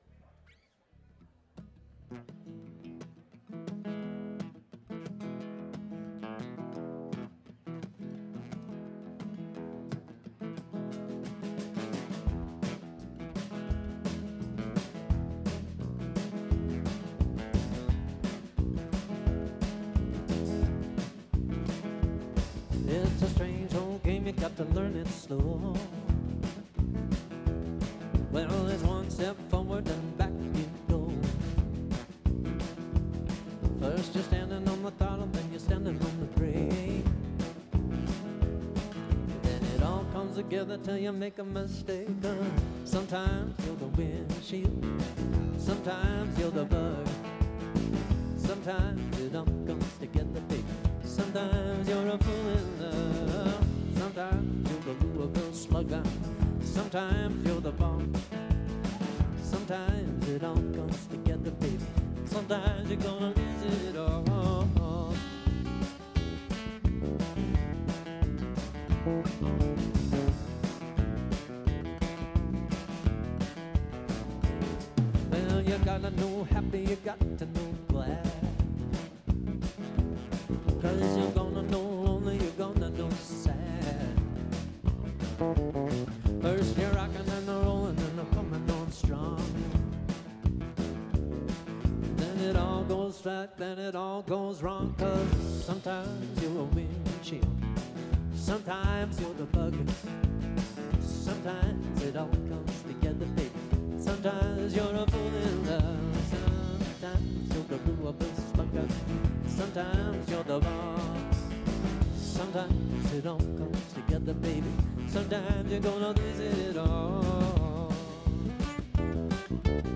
Live Audio - 3 Piece - (guitar, bass, drums)